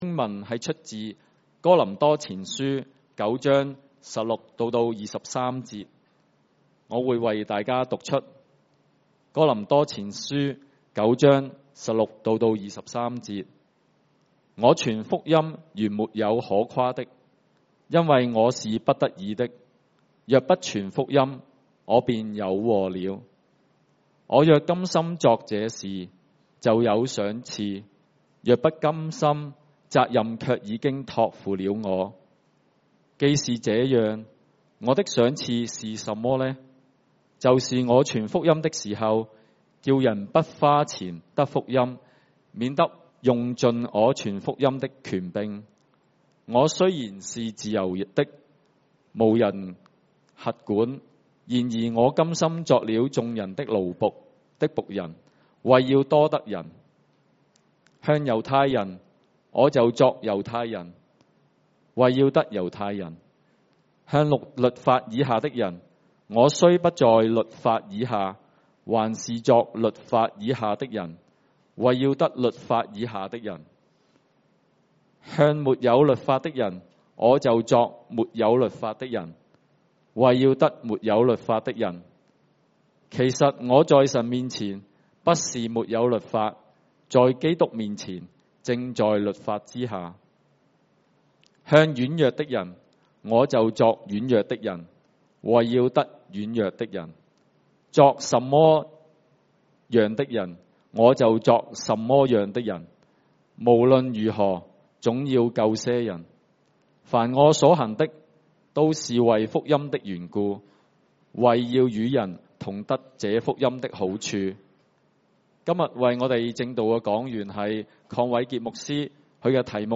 華埠粵語三堂 標籤